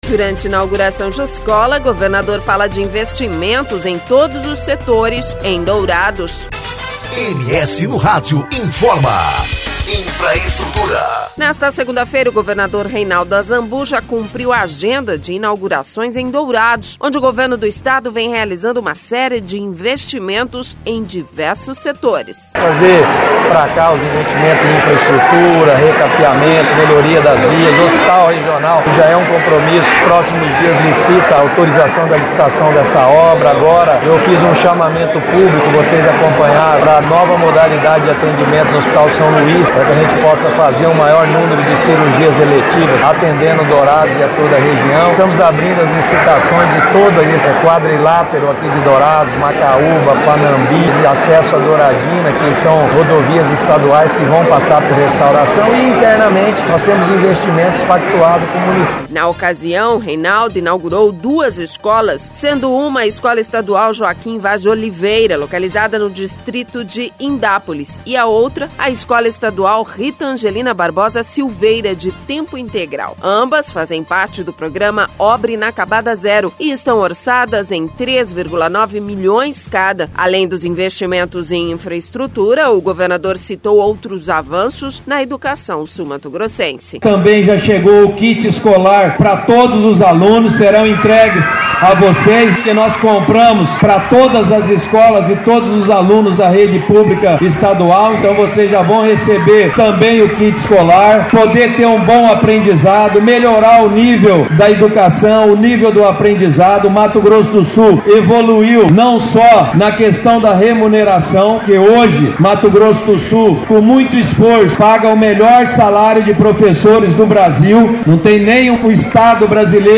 Durante inauguração de escolas, governador fala de investimentos em todos os setores em Dourados